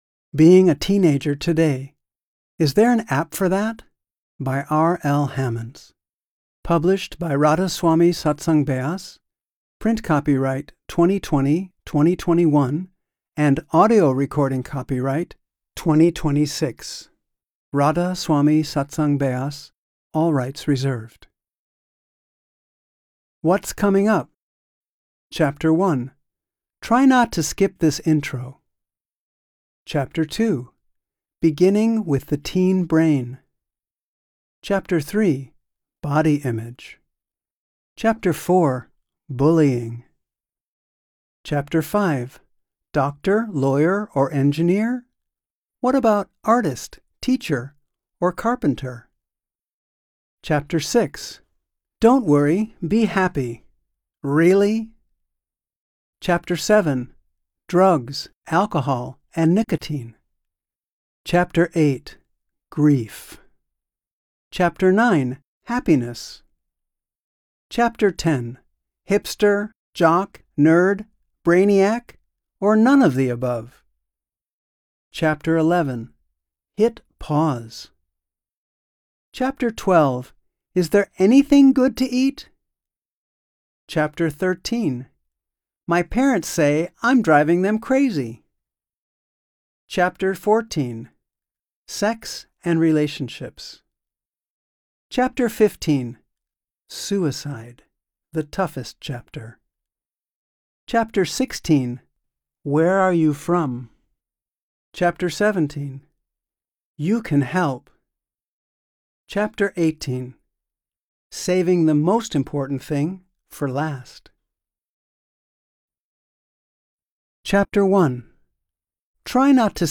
Being a Teenager Today - RSSB Audio Books